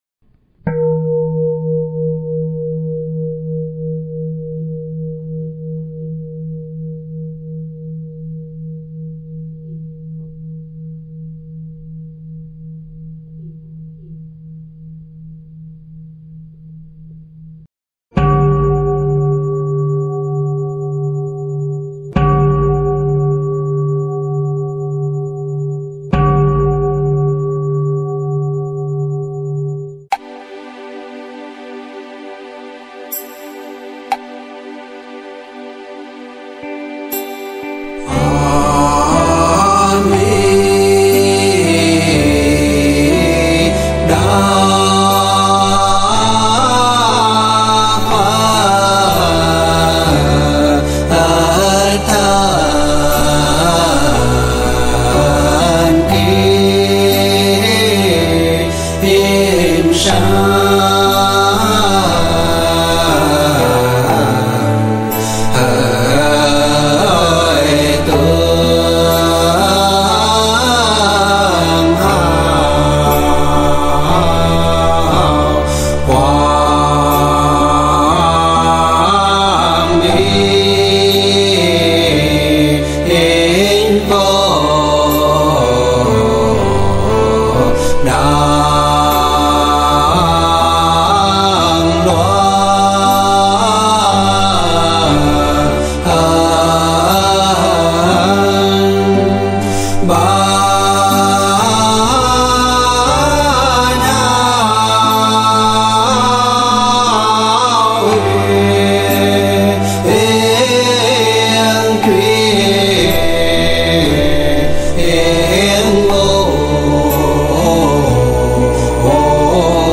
Nhạc Niệm Phật
Thể loại: Nhạc Niệm Phật